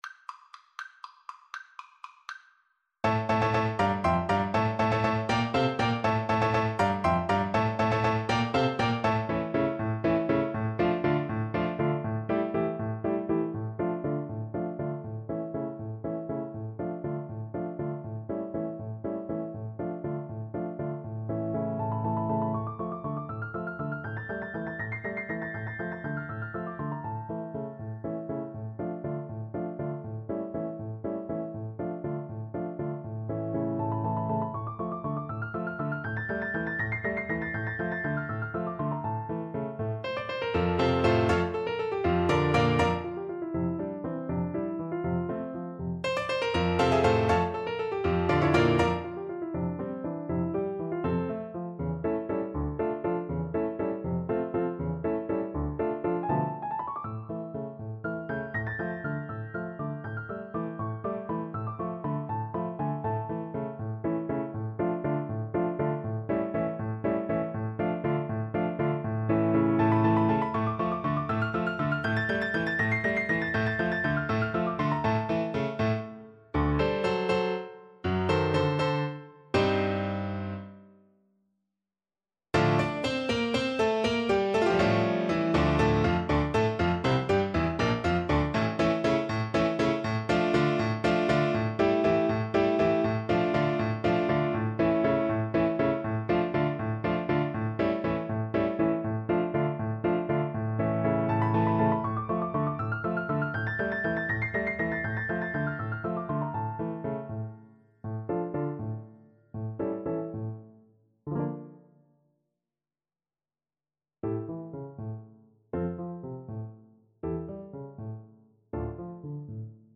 3/8 (View more 3/8 Music)
Allegro vivo (.=80) (View more music marked Allegro)
D minor (Sounding Pitch) (View more D minor Music for Flute )
Classical (View more Classical Flute Music)